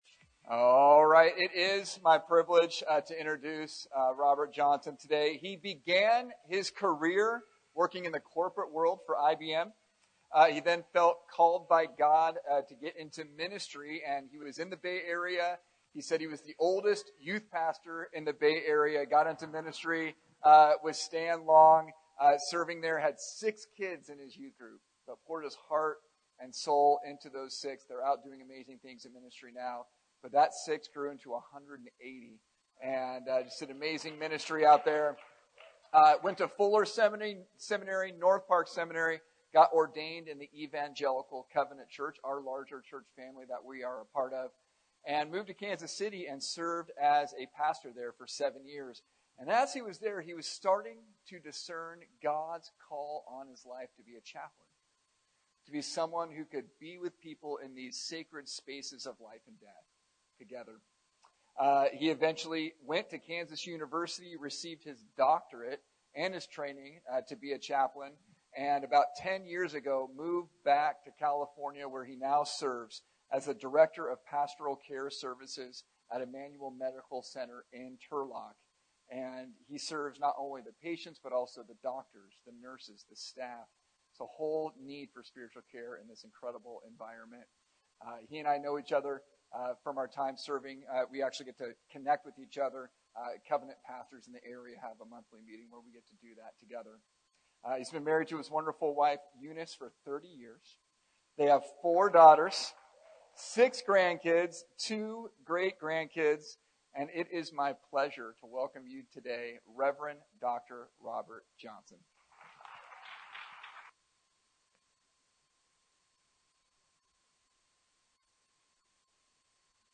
Sermons | Covenant Grove Church